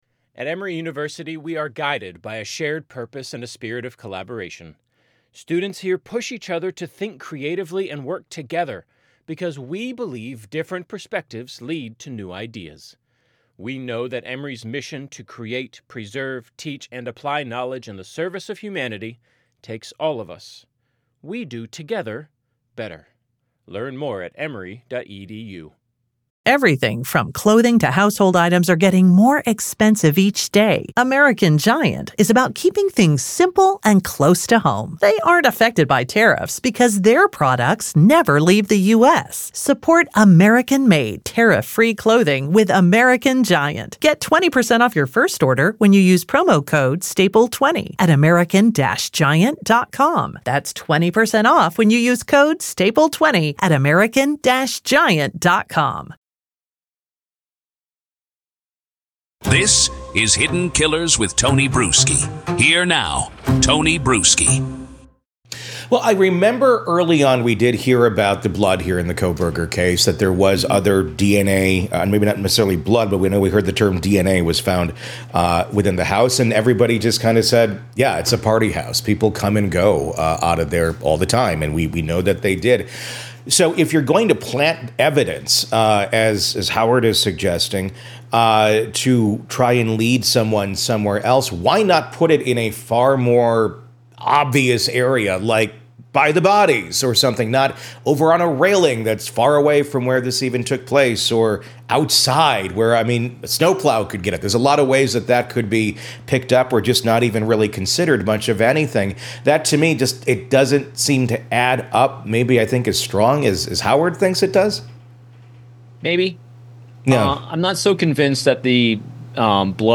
retired FBI Special Agent, breaks it all down